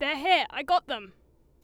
Voice Lines / Barklines Combat VA